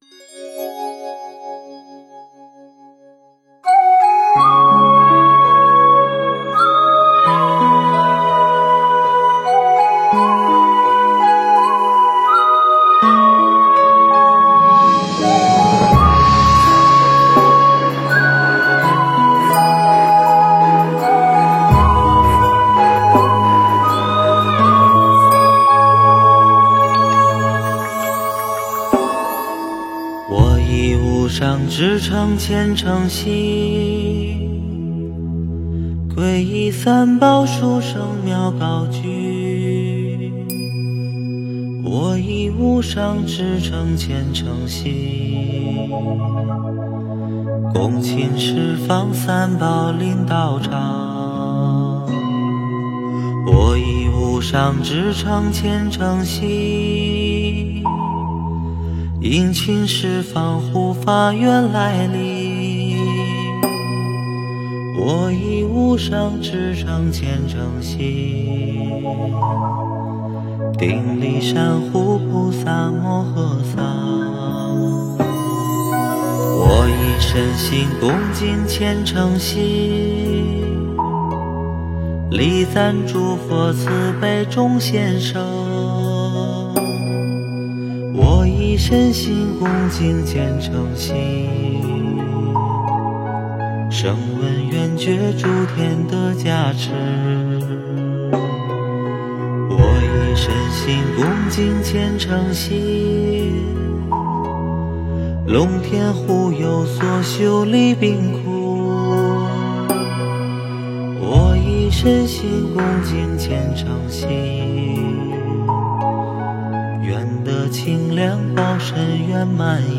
佛音 凡歌 佛教音乐 返回列表 上一篇： 心无罣碍(梵文唱诵-轻快版